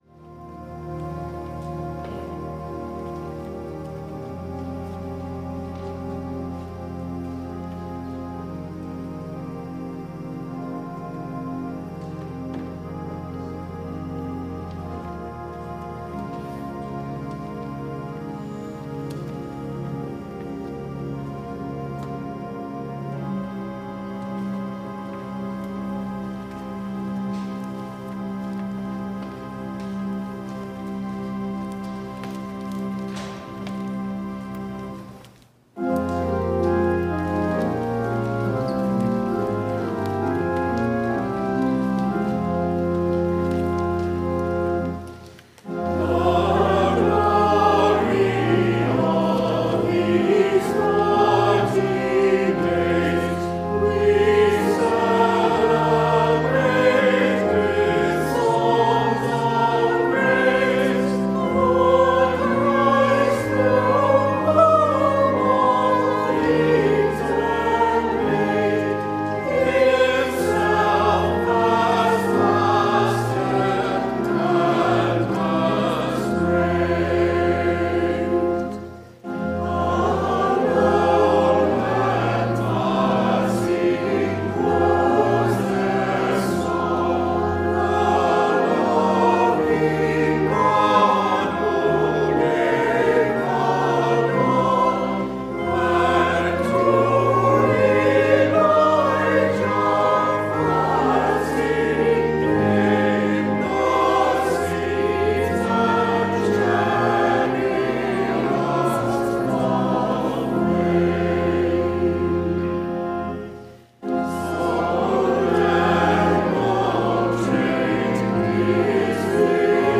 Podcast from Christ Church Cathedral Fredericton
WORSHIP - 7:30 p.m. Ash Wednesday